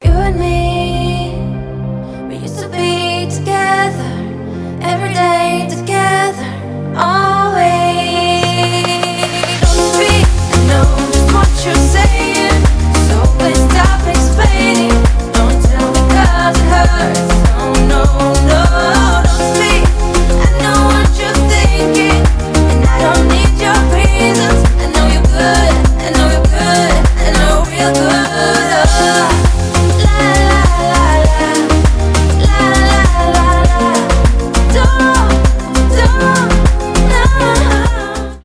танцевальные , club house